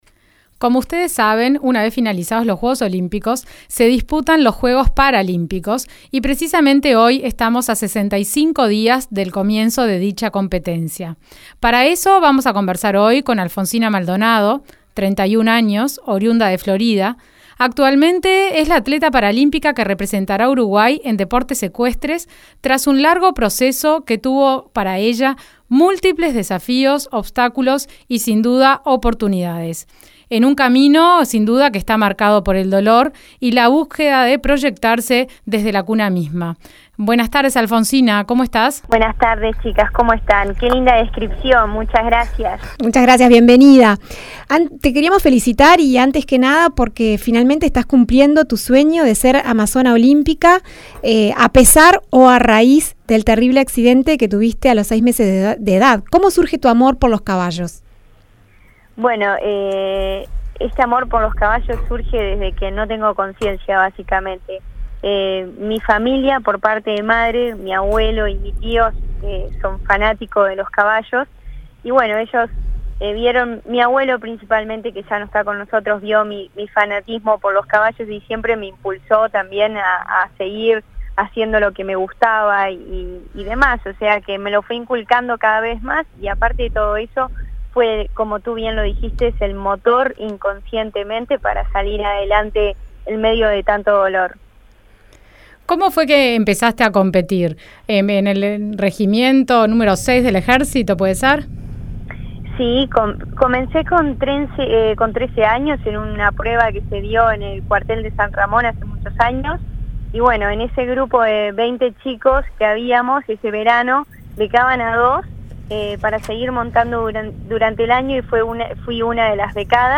Juegos Paralímpicos